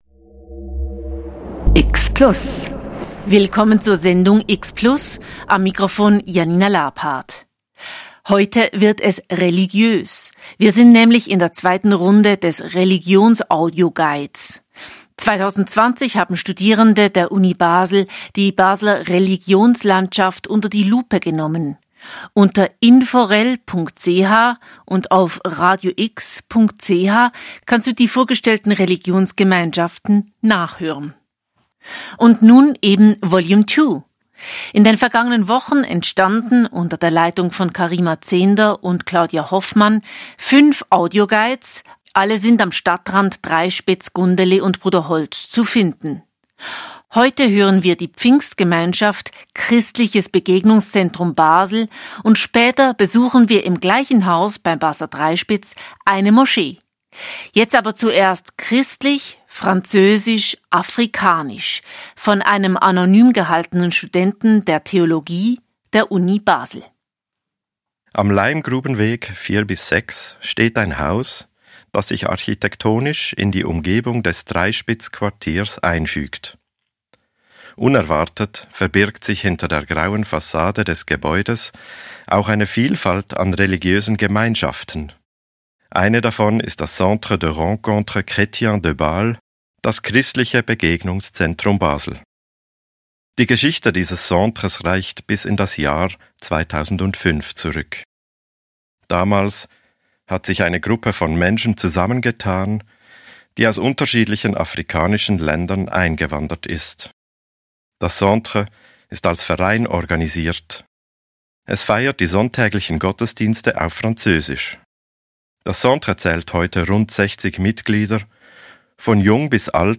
Sie recherchierten, nahmen an Radiocrashkursen teil, interviewten Fachpersonen der entsprechenden Religionsgemeinschaft, schrieben Manuskripte und standen am Mikrofon. Daraus entstanden fünf Religionsaudioguides.